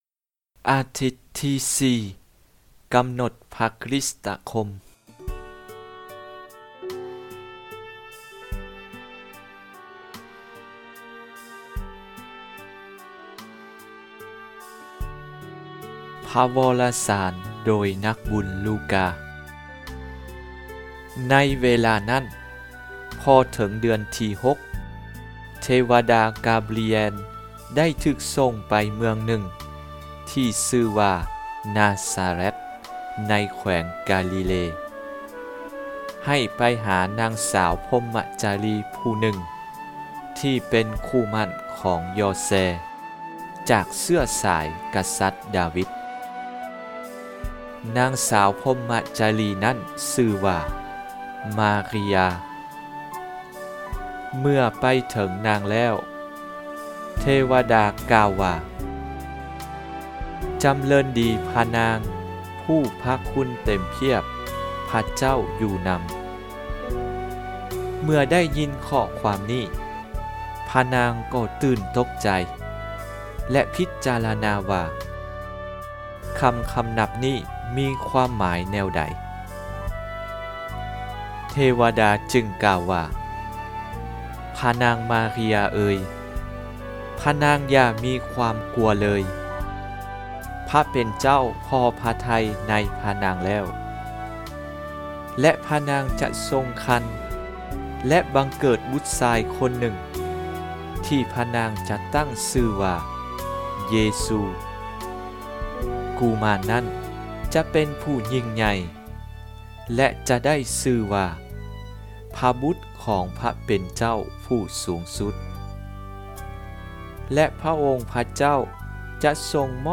HomelieDimanche_4_Avent.mp3